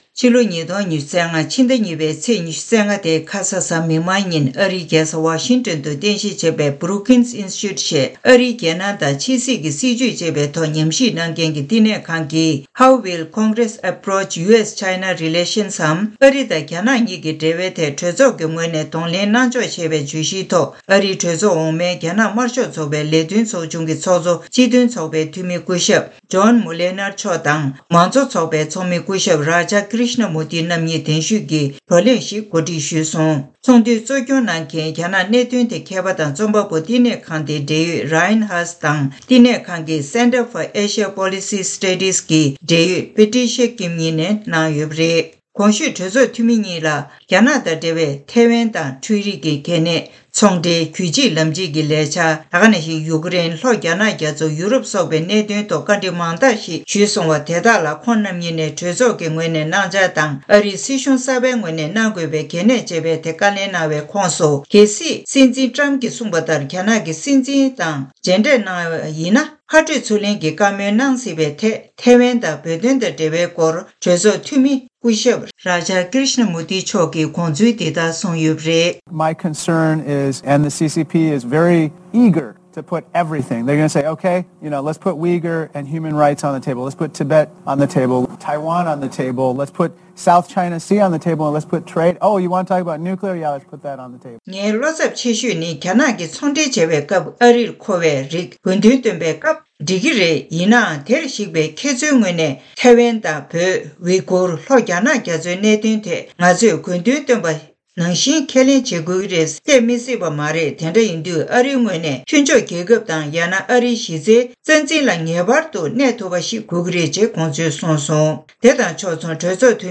གསར་འགྱུར་དཔྱད་གཏམ་གྱི་ལེ་ཚན་ནང་།